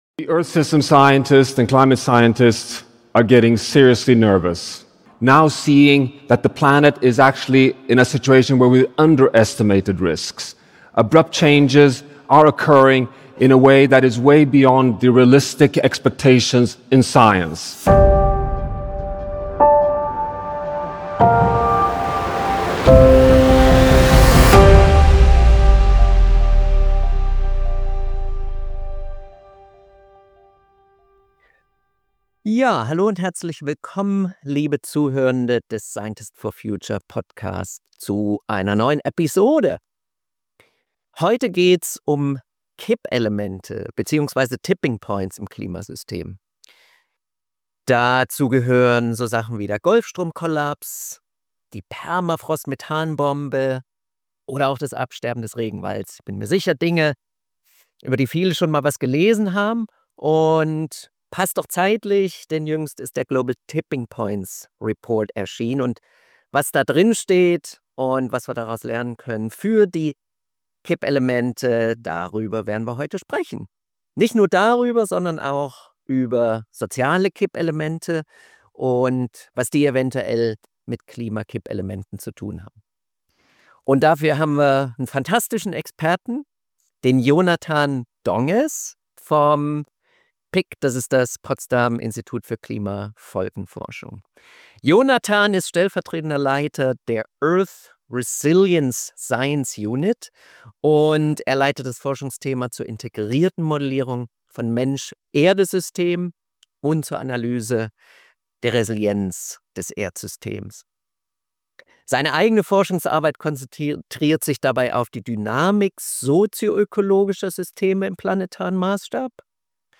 Der S4F Podcast: Gespräche mit Wissenschaftler*innen über die Klimakrise, Nachhaltigkeit und eine lebenswerte Zukunft.